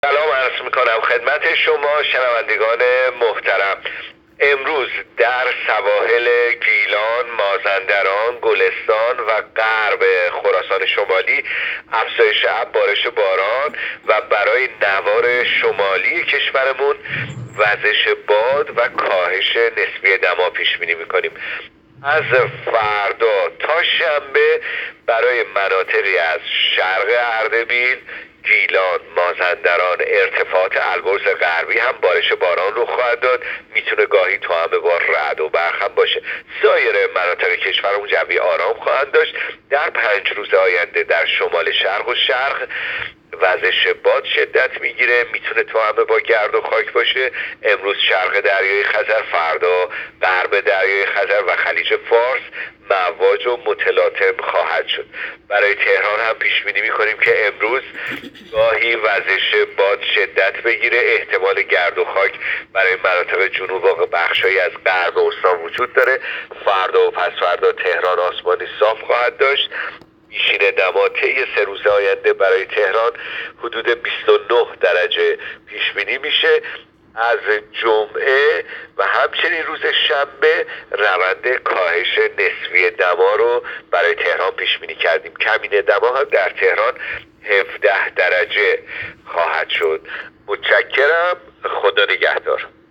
گزارش رادیو اینترنتی پایگاه‌ خبری از آخرین وضعیت آب‌وهوای ۲۲ مهر؛